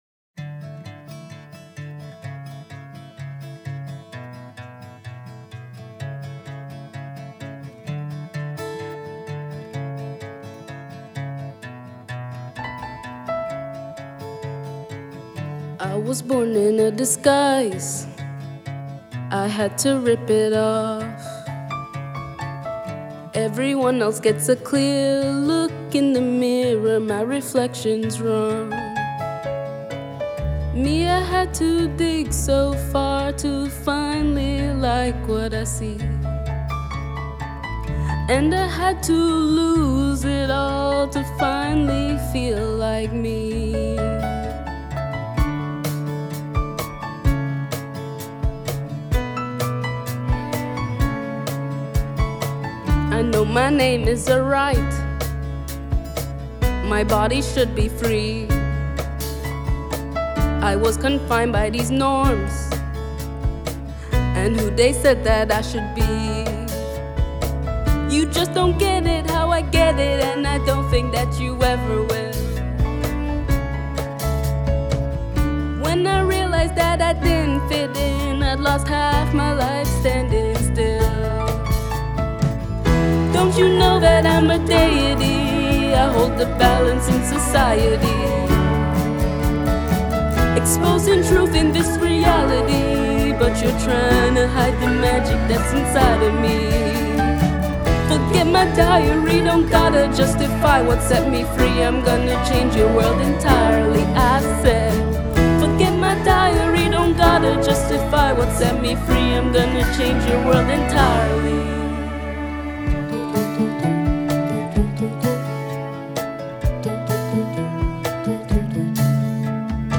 lead vocals
harmonies and background vocals
bass
piano
cello, drums